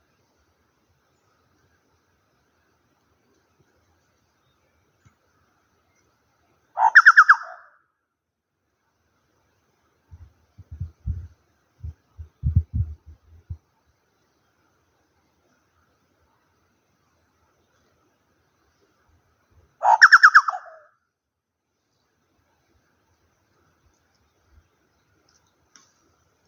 Chucao (Scelorchilus rubecula)
Nombre en inglés: Chucao Tapaculo
Fase de la vida: Adulto
Localización detallada: Senda en Cerro Bayo
Condición: Silvestre
Certeza: Observada, Vocalización Grabada